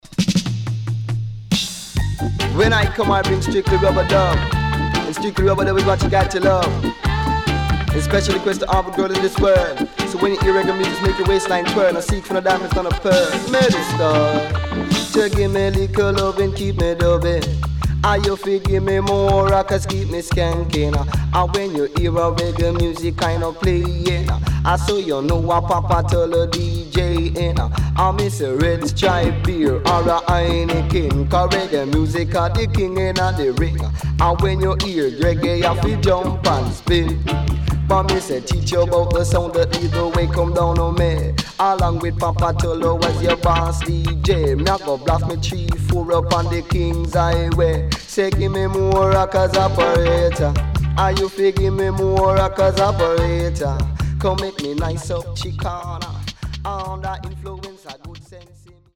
Early80's Killer Digital Roots
SIDE A:少しチリノイズ入りますが良好です。